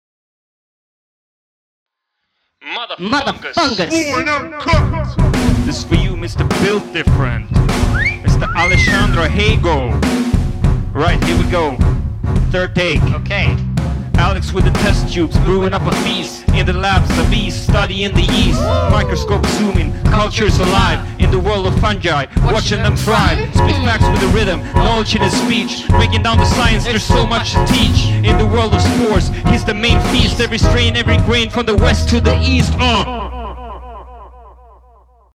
yeast-rap.mp3